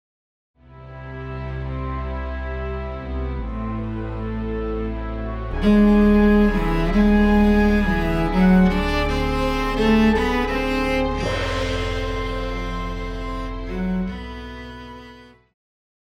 Pop
Cello
Band
Instrumental
World Music,Electronic Music
Only backing